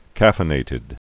(kăfĭ-nātĭd)